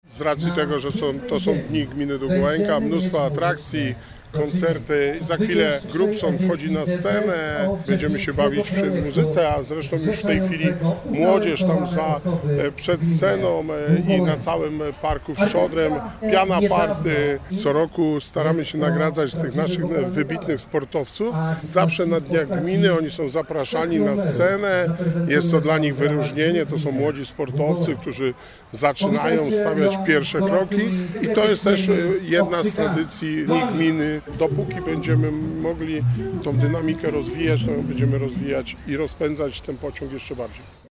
W Parku w Szczodrem odbył się piknik, dopisały frekwencja oraz pogoda.
– Bardzo mocno inwestujemy w rozwój naszej gminy, po to żeby mieszkańcom się dobrze żyło. A dziś chcemy świętować razem w parku w Szczodrem – mówi Wojciech Błoński, wójt gminy Długołęka.